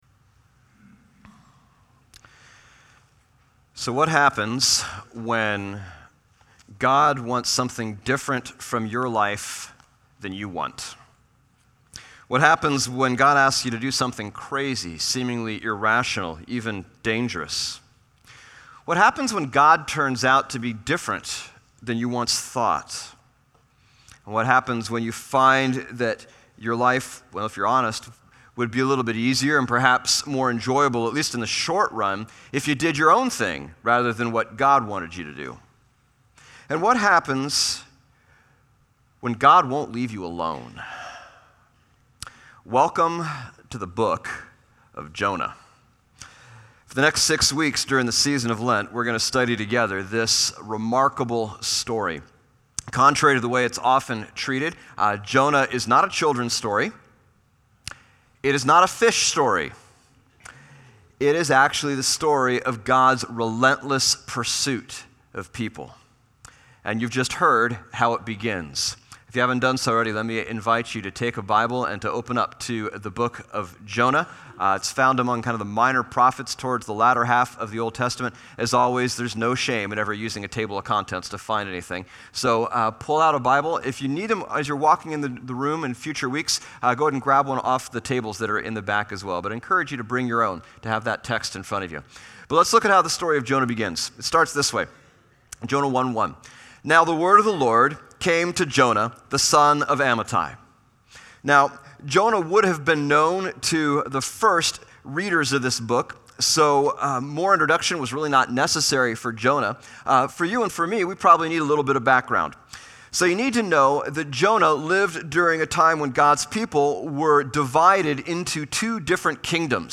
Lenten Sermon Series on Jonah